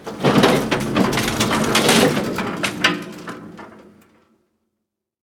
gurneyload.ogg